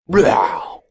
normal zombie die 1.mp3